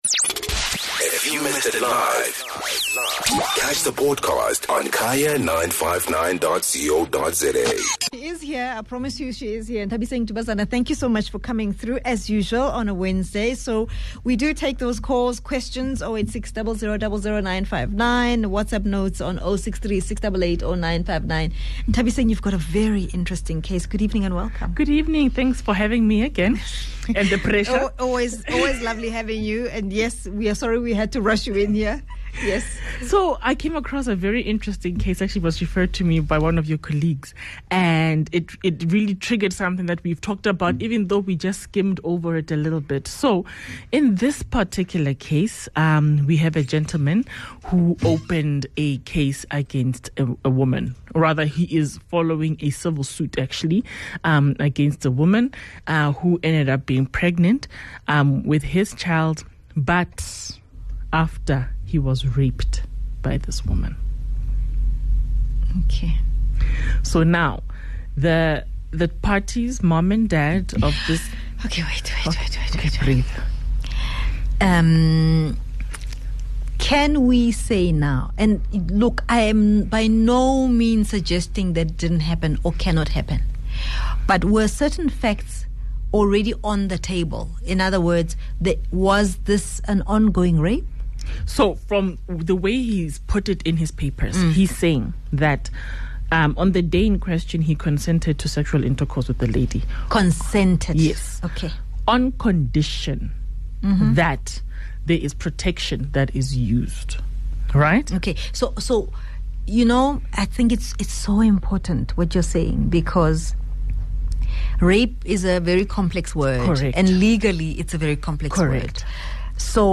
Legal Analyst